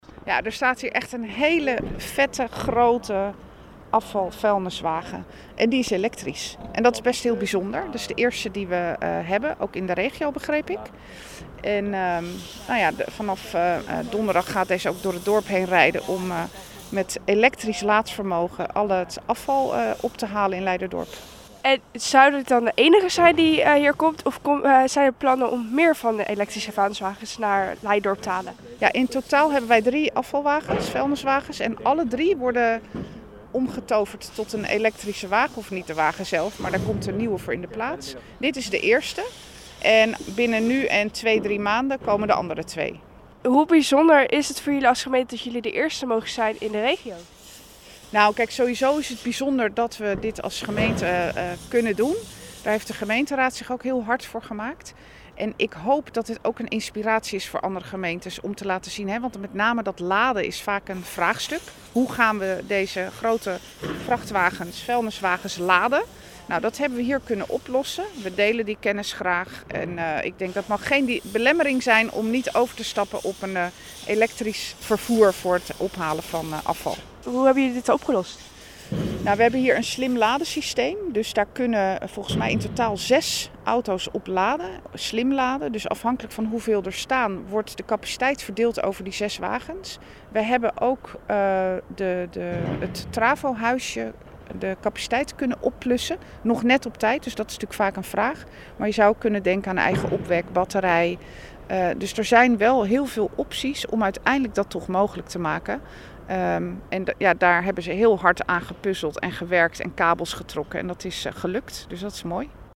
Wethouder Gebke van Gaal over de eerste elektrische vuilniswagen van Leiderdorp: